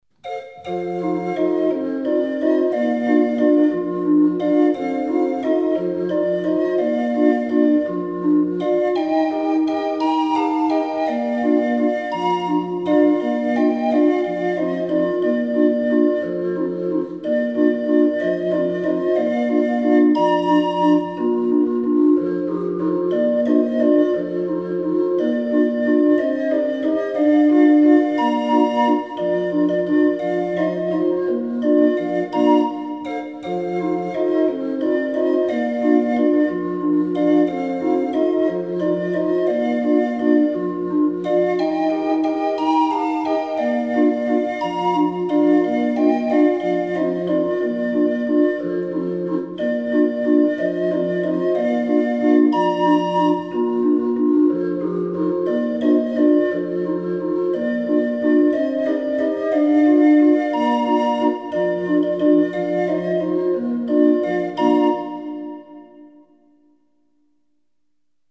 20 street organ